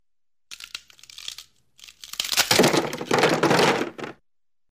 Снег звуки скачать, слушать онлайн ✔в хорошем качестве